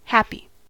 happy: Wikimedia Commons US English Pronunciations
En-us-happy.WAV